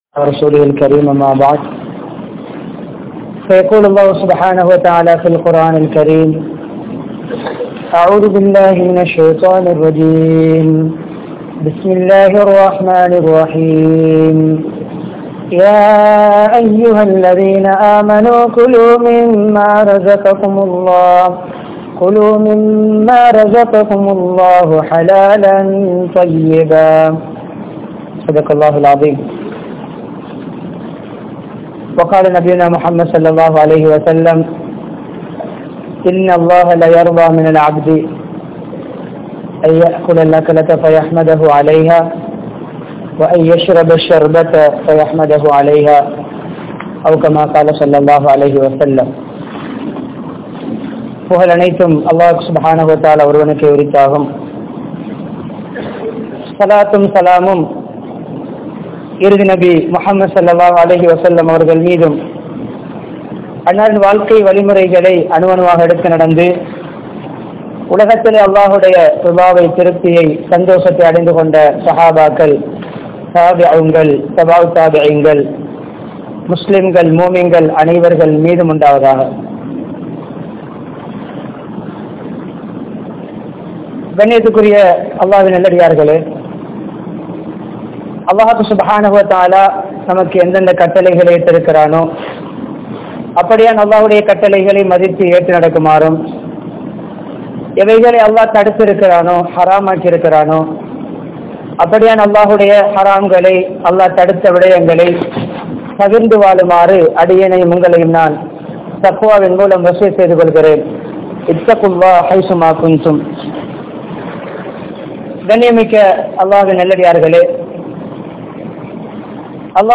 Evvaaru Unna Veandum? (எவ்வாறு உண்ண வேண்டும்?) | Audio Bayans | All Ceylon Muslim Youth Community | Addalaichenai